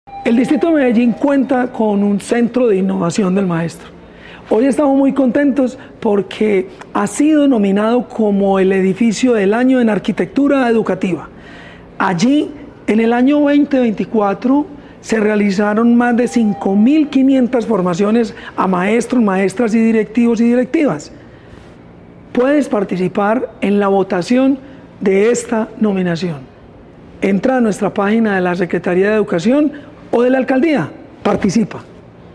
Palabras de Jorge Iván Ríos Rivera, subsecretario de la Prestación del Servicio Educativo